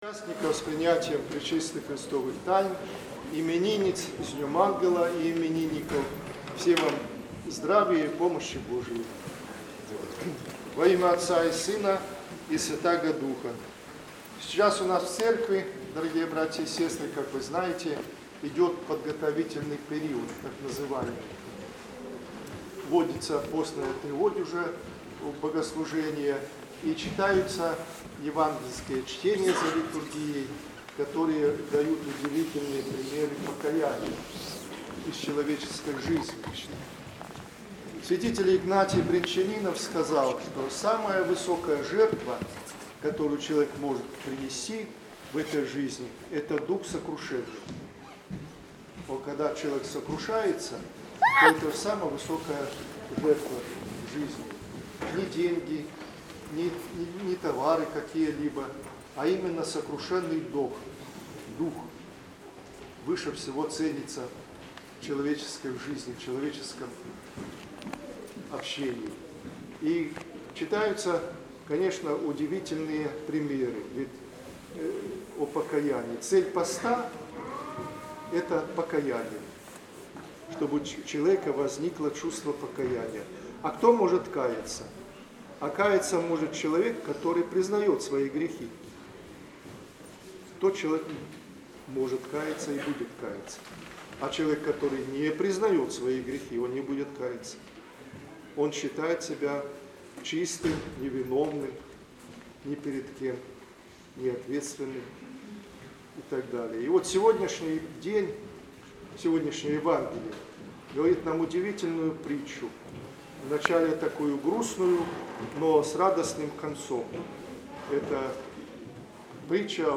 Воскресная Божественная литургия была отслужена в Екатерининском храме.